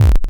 tavern_damage.wav